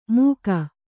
♪ mūka